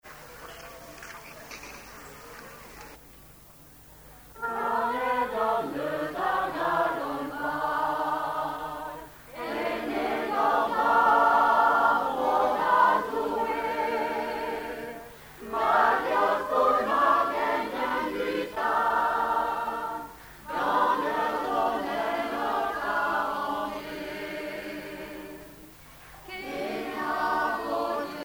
Informateur(s) Mor-Gan Chorale
Genre strophique
Chansons de la soirée douarneniste 88
Pièce musicale inédite